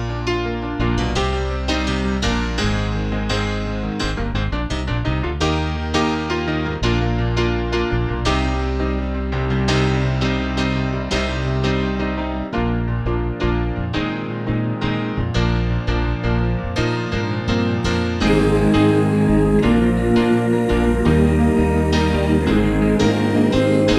One Semitone Down Rock 8:06 Buy £1.50